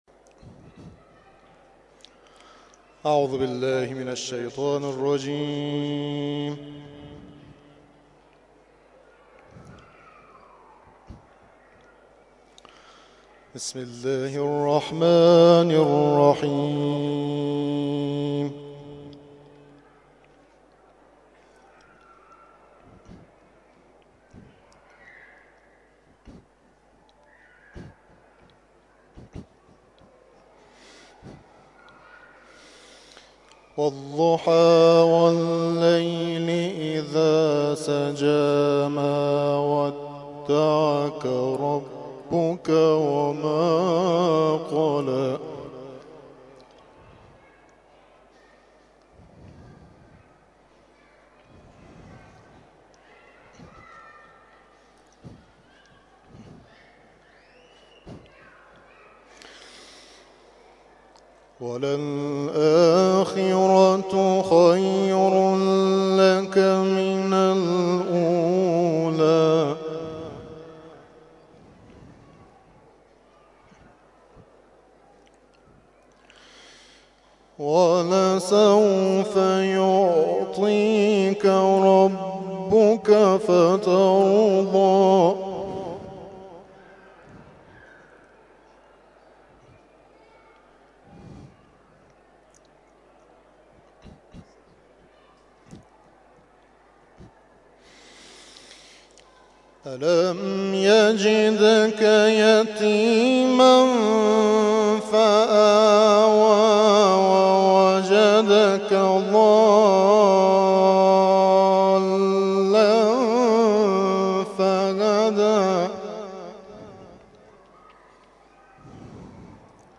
(مغرب)
تلاوت مغرب